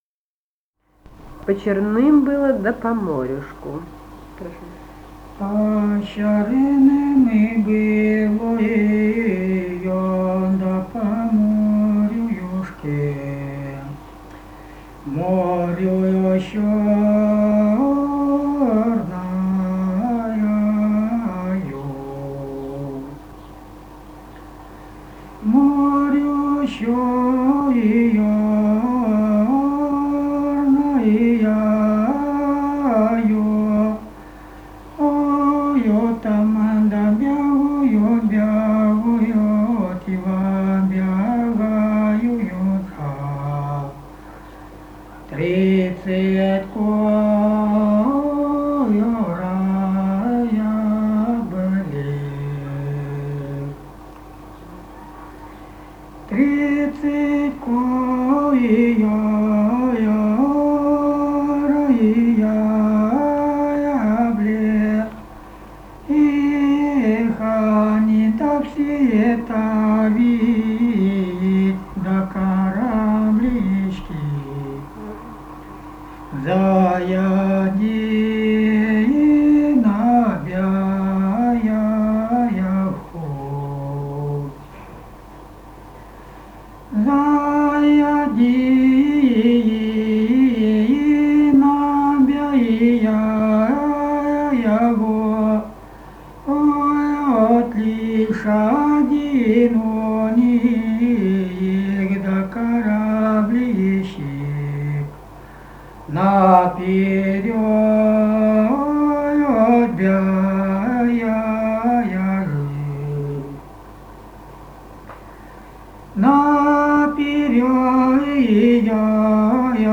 Ставропольский край, с. Бургун-Маджары Левокумского района, 1963 г. И0728-11